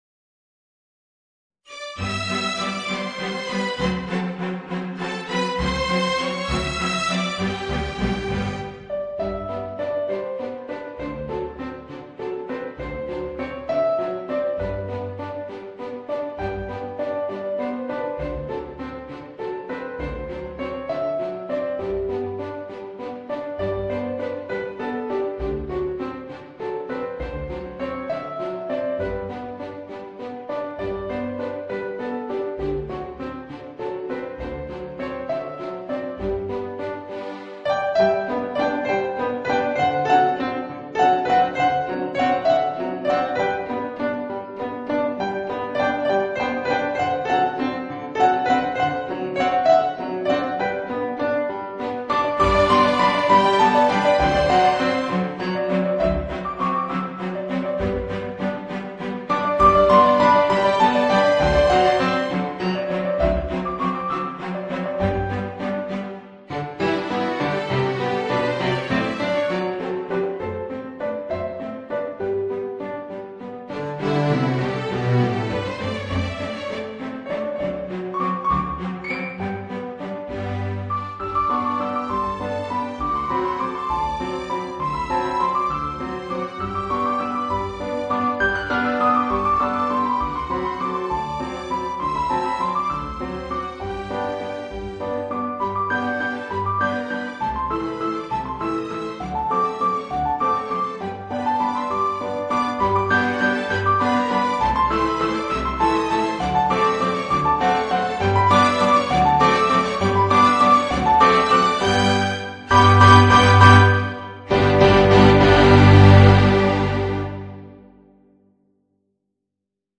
Voicing: Piano and String Quintet